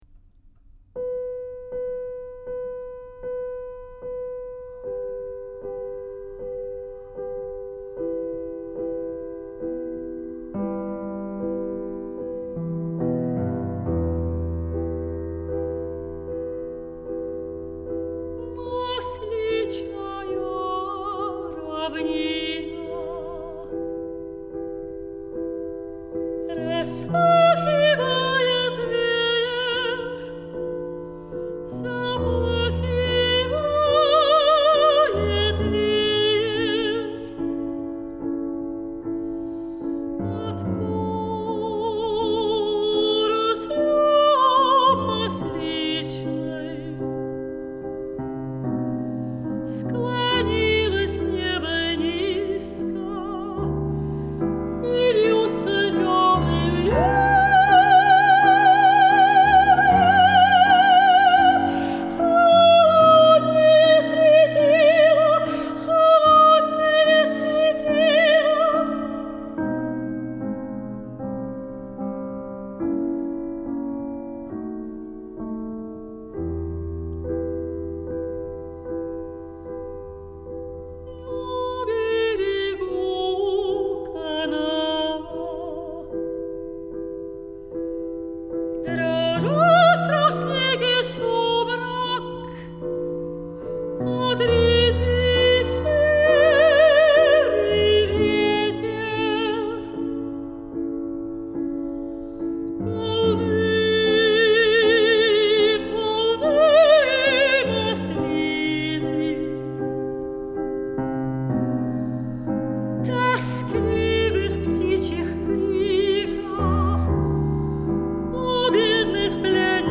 Singing
Piano